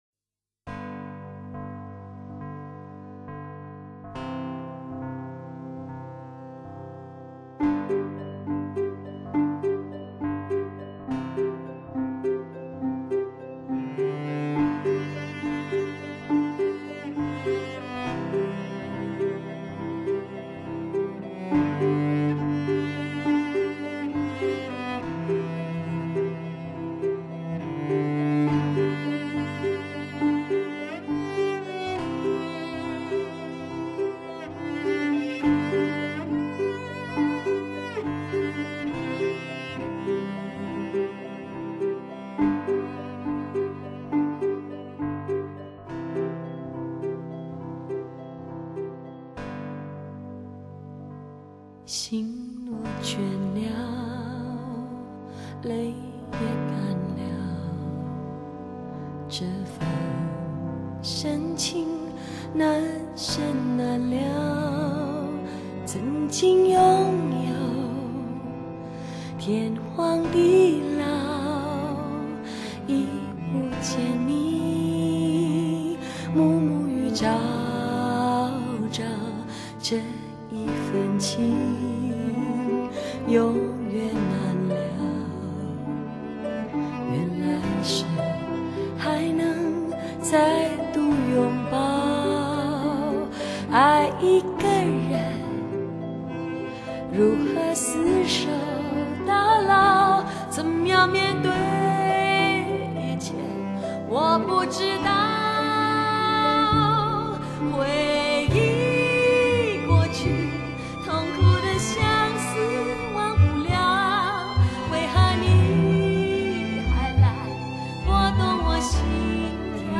6.1DTS-CD环绕音乐，历年珍藏多轨母带重新混音，
这次首次推出的是DTS ES/DTS扩展环绕6.0格式的CD。
是基于5.0环绕的基础上再增加多一个后中置。